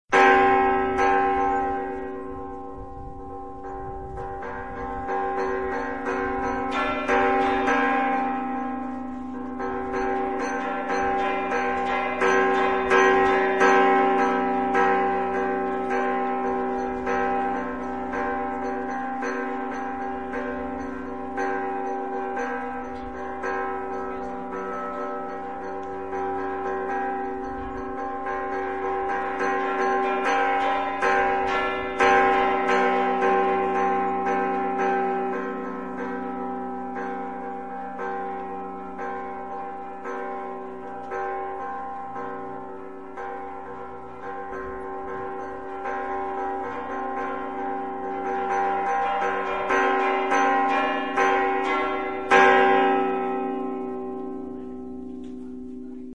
A continuació podeu escoltar uns quants "tocs" de campanes:
Repicons (Campaners de Solsona)
repicons.mp3